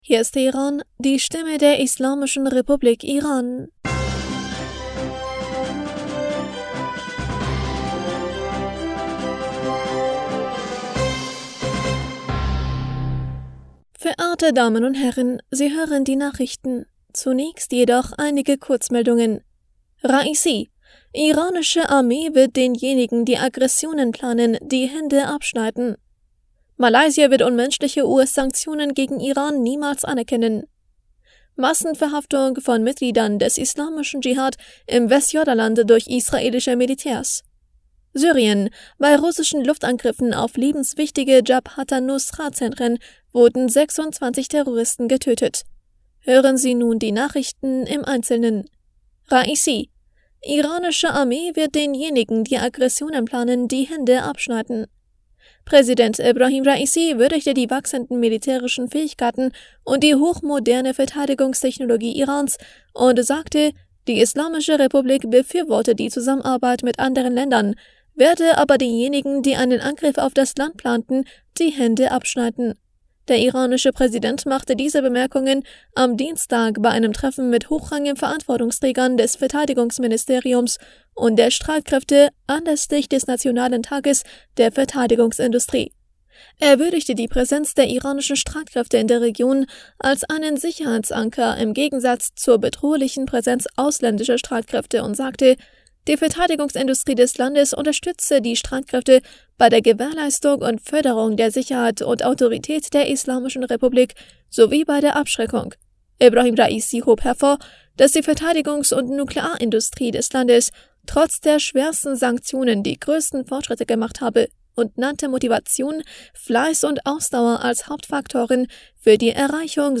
Nachrichten vom 23. August 2023